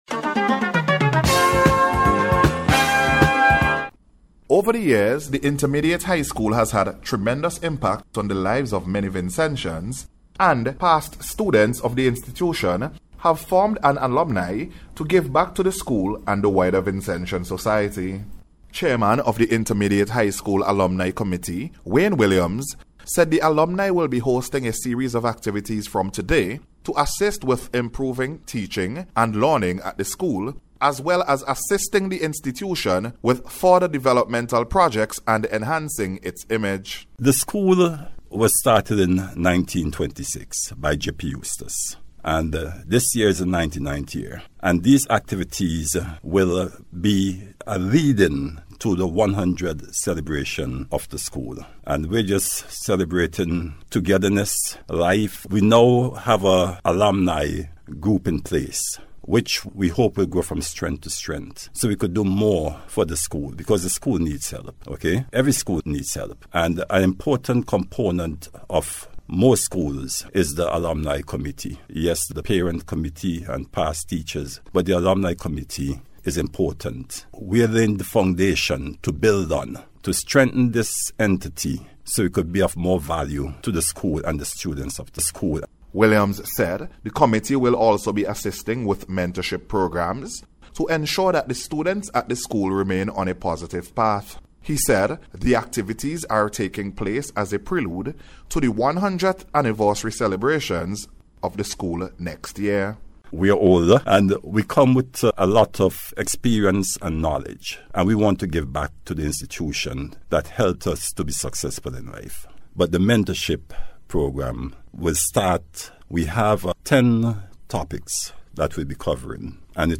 INTERMEDIATE-HIGH-SCHOOL-99TH-ANNIVERSARY-REPORT.mp3